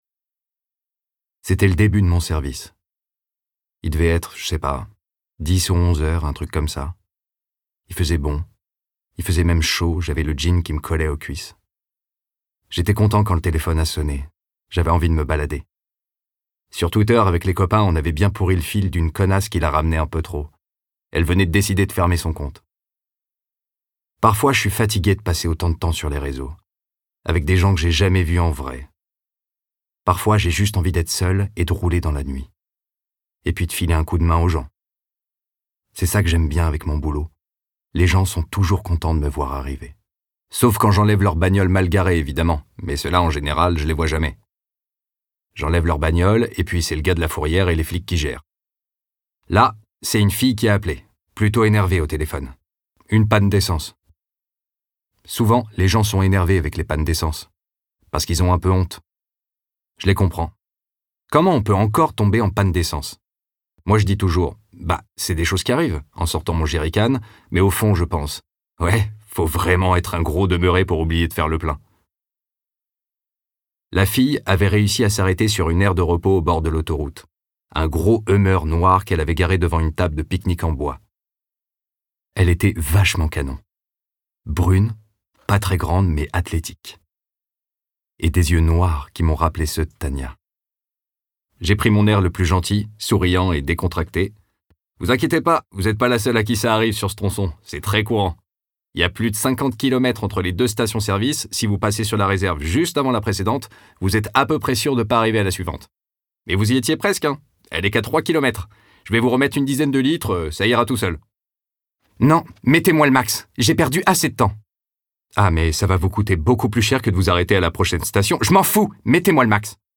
Démo Livre audio
20 - 55 ans - Baryton Ténor